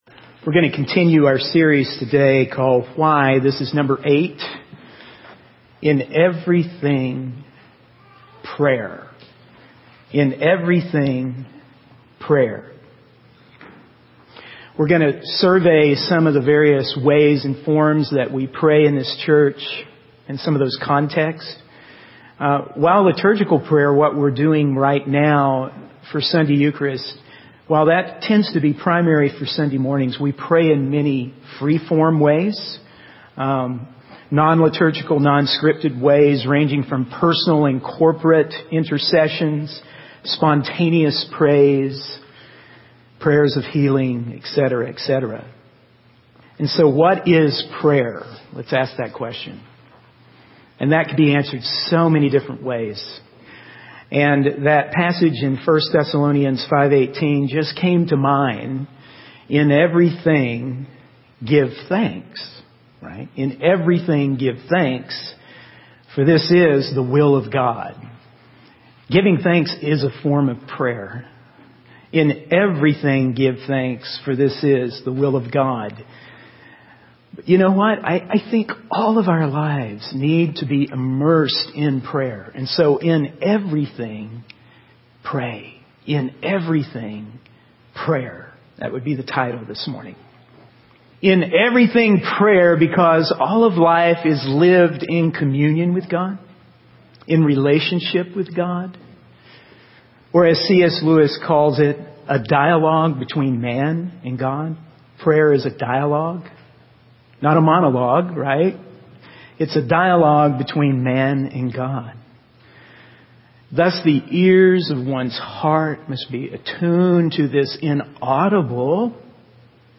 In this sermon, the speaker emphasizes the importance of prayer and how it should be done in the name of Jesus Christ and the Father, Son, and Holy Spirit. The speaker highlights the need to take the time for prayer, even in our fast-paced culture.